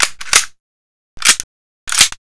k98_worldreload.wav